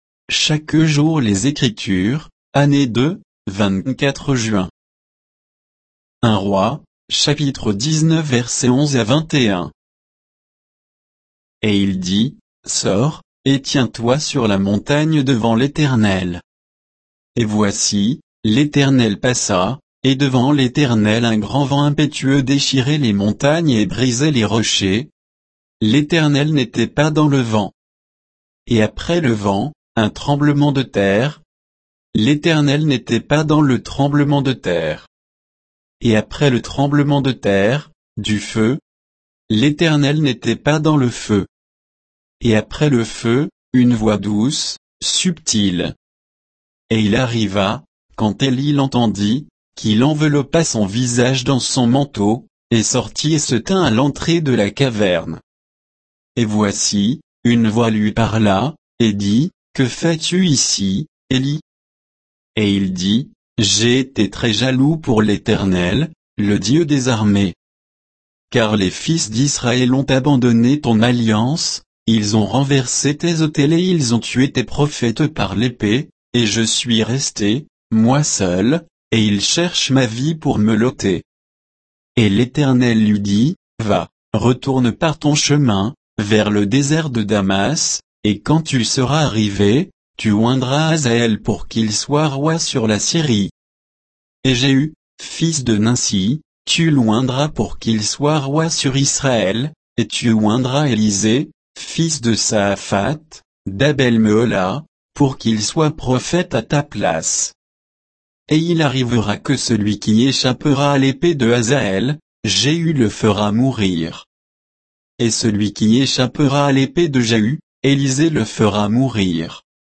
Méditation quoditienne de Chaque jour les Écritures sur 1 Rois 19